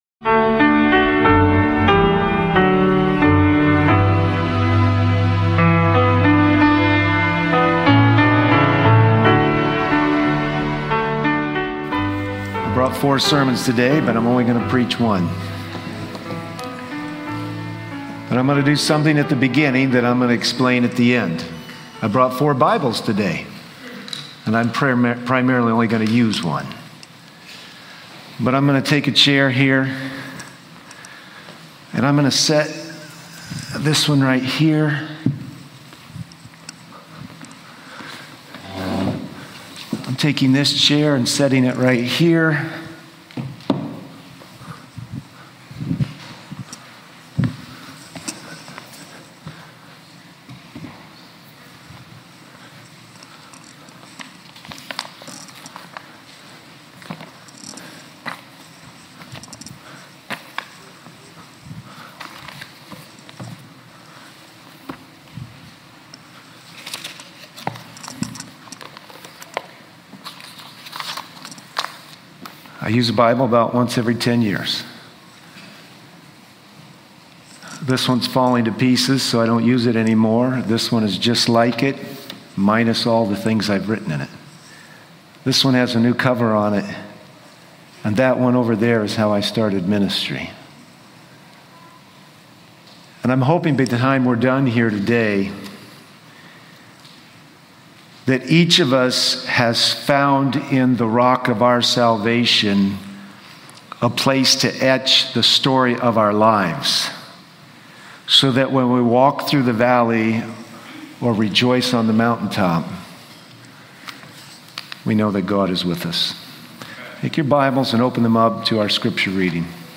– 💡 Faith vs. Sight : The sermon encourages believers to live by faith rather than relying solely on visible evidence, which can lead to fear and doubt.